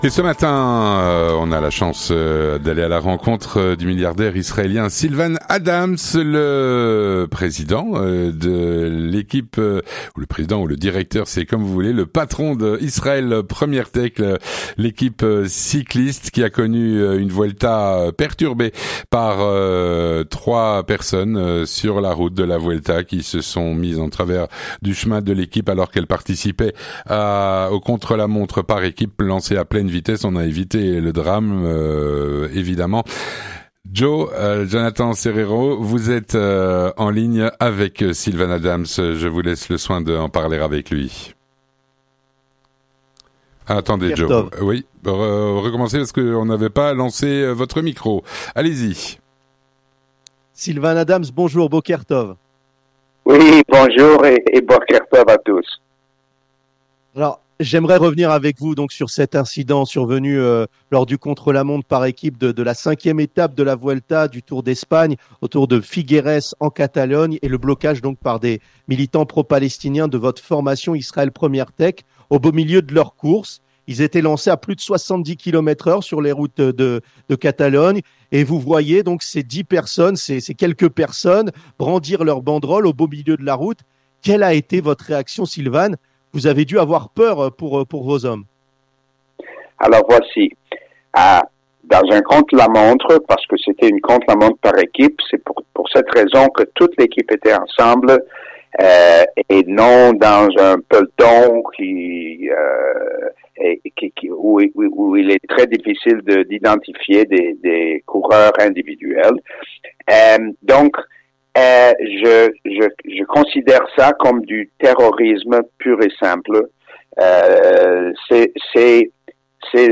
Rencontre avec - Sylvan Adams, le mécène milliardaire israélien.
On en parle avec le patron de cette équipe, Sylvan Adams, mécène et milliardaire israélien, qui voue une partie de sa fortune pour qu'Israël brille à travers le monde par le biais du Sport.